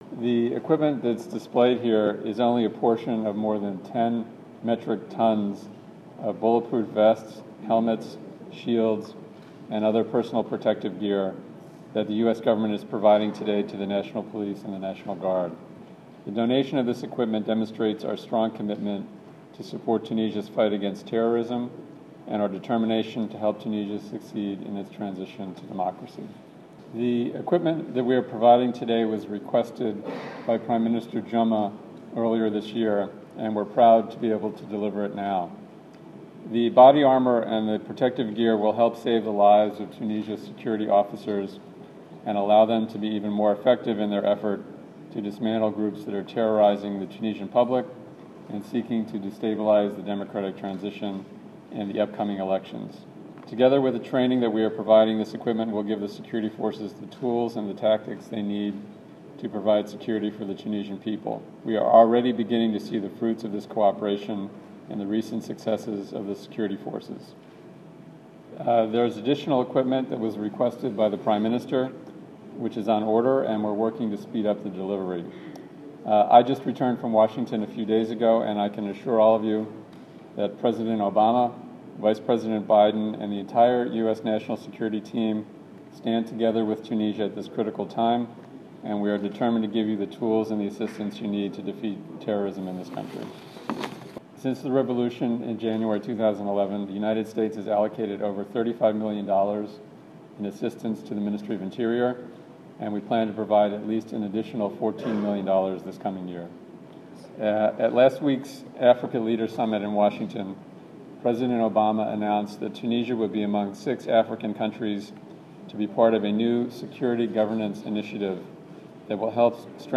سفير الولايات المتحدة الأمريكية بتونس